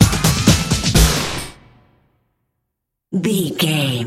Aeolian/Minor
Fast
drum machine
synthesiser
electric piano
90s
Eurodance